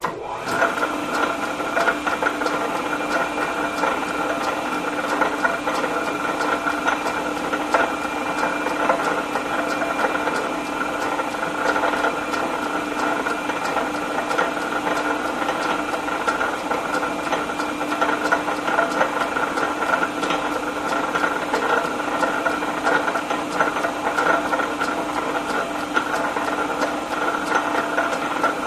Gears Lathe With Leather Belt Pulley Rattle Clicks, High